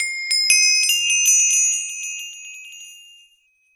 levelup.ogg